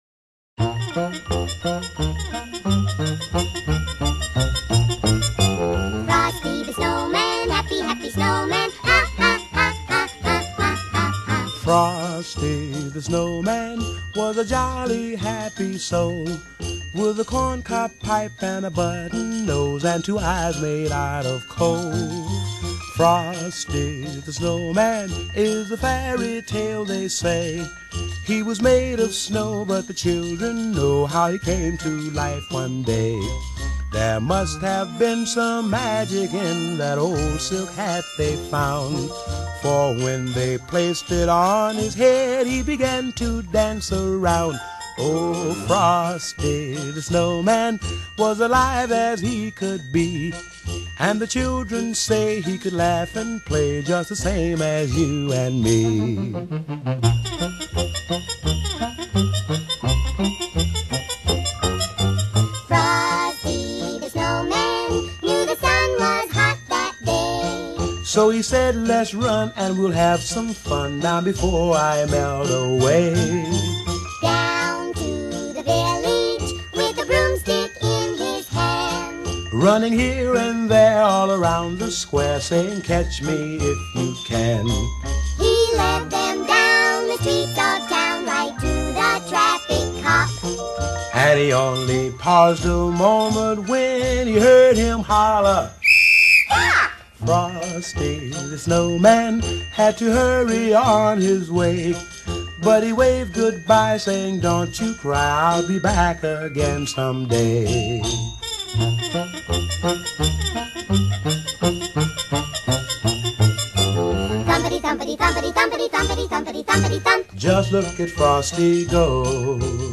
Genre: Jazz, Swing, Holiday, Oldies